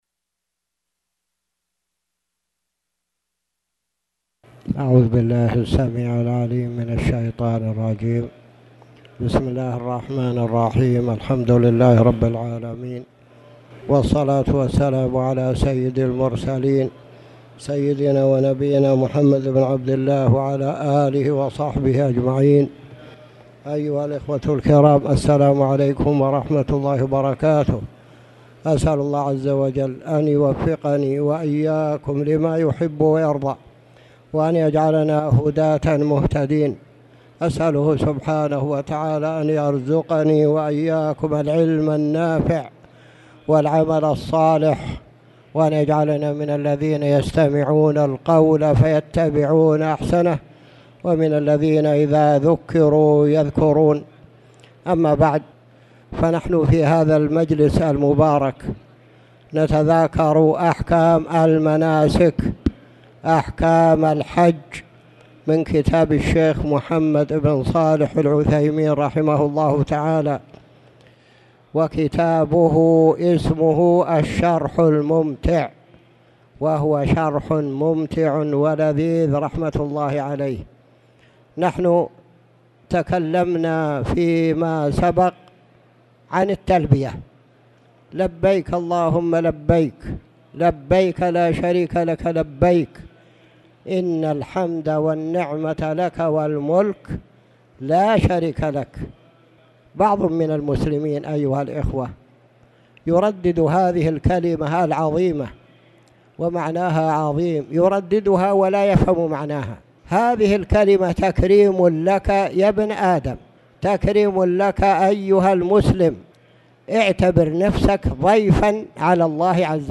تاريخ النشر ٢٠ ذو القعدة ١٤٣٨ هـ المكان: المسجد الحرام الشيخ